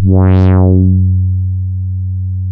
MG MOD.F#2 1.wav